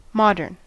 Ääntäminen
GenAm: IPA : /ˈmɑdɚn/